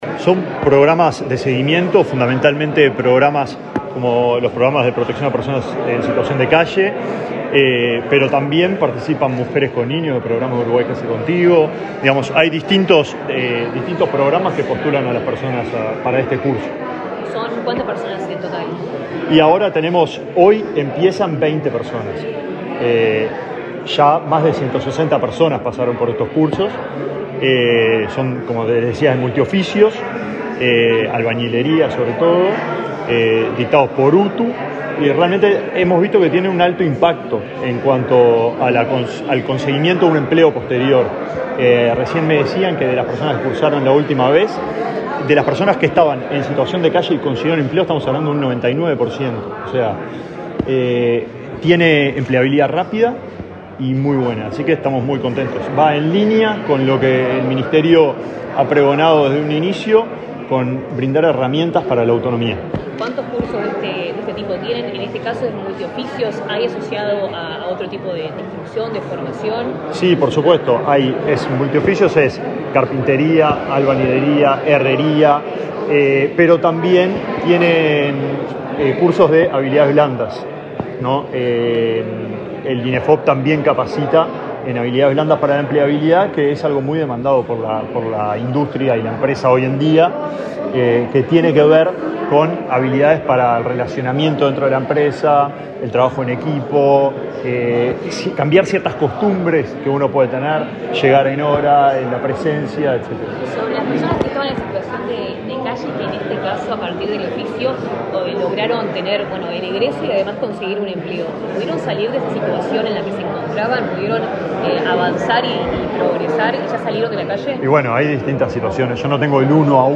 Declaraciones del ministro de Desarrollo Social, Alejandro Sciarra